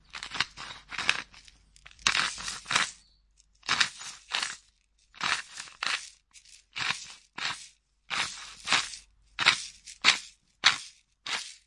描述：塑料蛋振动器
标签： 塑料 180bpm 振动筛 打击乐
声道立体声